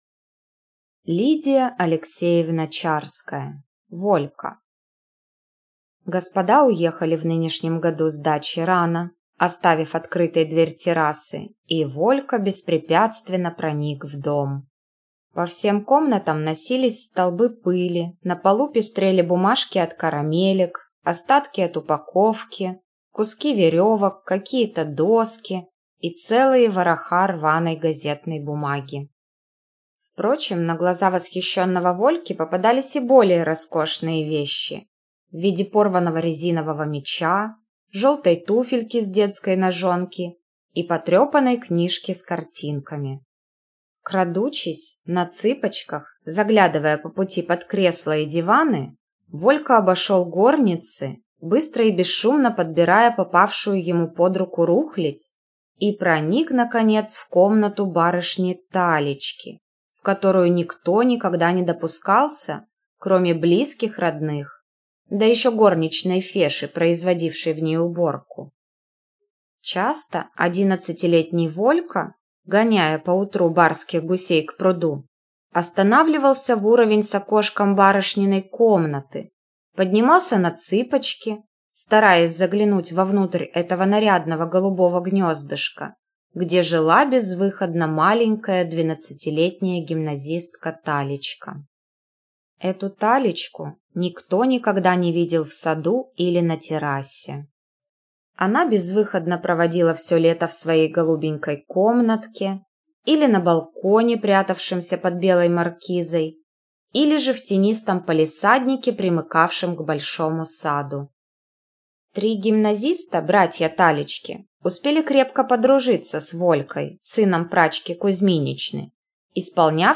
Aудиокнига Волька Автор Лидия Чарская Читает аудиокнигу